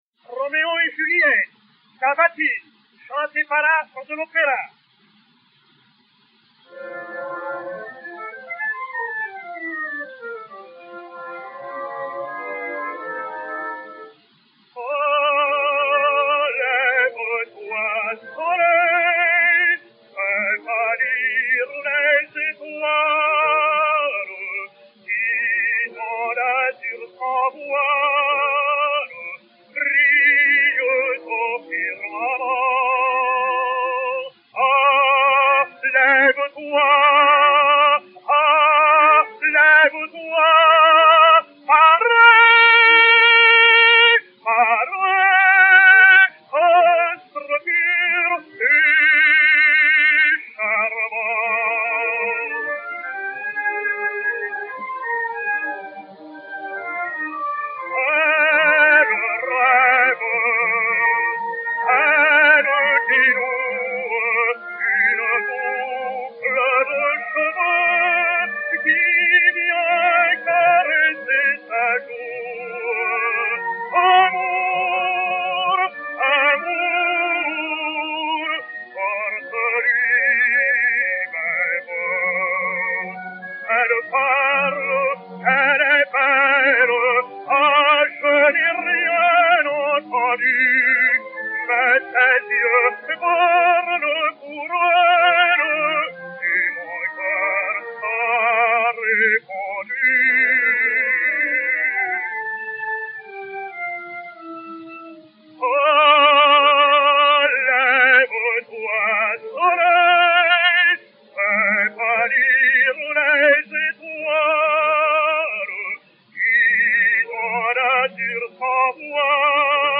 Cavatine
Agustarello Affre (Roméo) et Orchestre
Pathé saphir 90 tours n° 3485 bis, réédité sur 80 tours n° 7, enr. à Paris en 1910